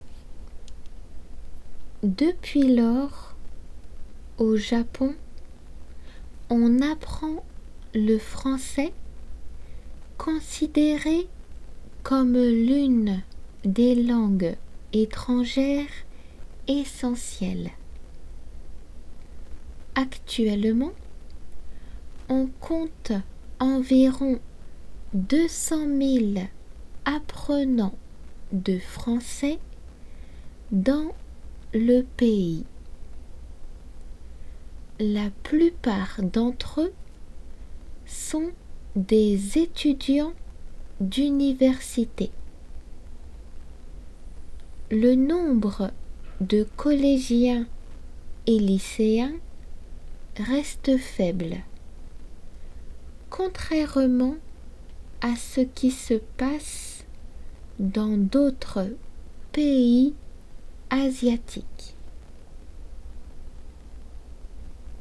仏検デイクテ―音声–1
普通の速さで